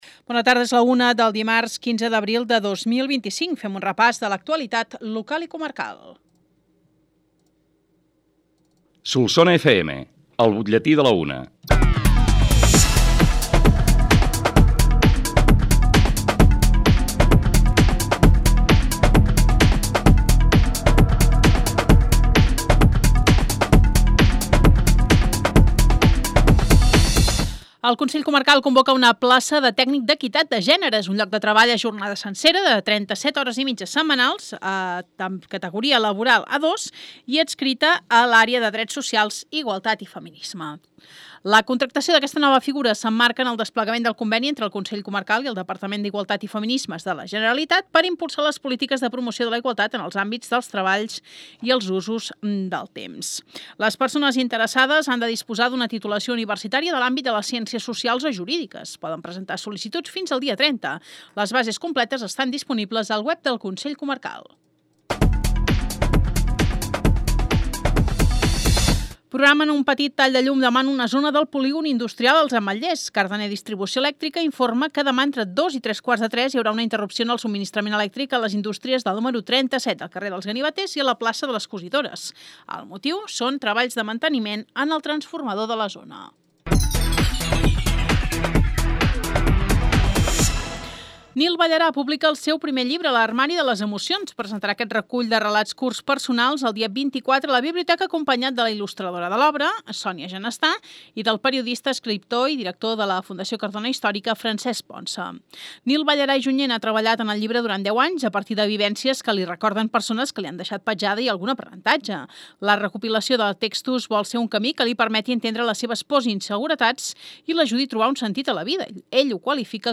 L’ÚLTIM BUTLLETÍ
BUTLLETI-15-ABRIL-25.mp3